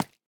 Minecraft Version Minecraft Version latest Latest Release | Latest Snapshot latest / assets / minecraft / sounds / block / candle / break5.ogg Compare With Compare With Latest Release | Latest Snapshot